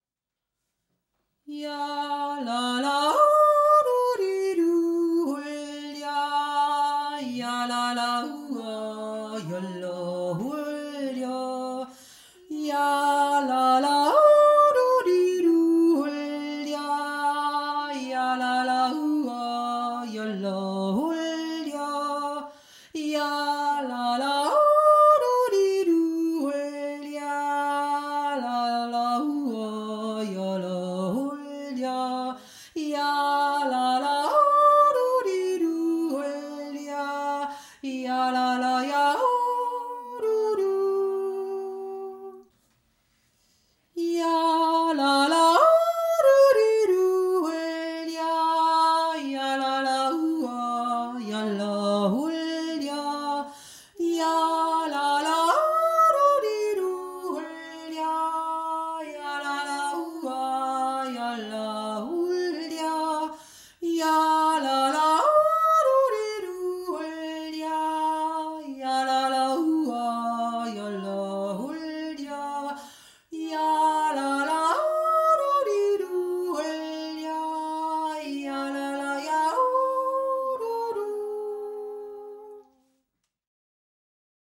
Hohe Stimme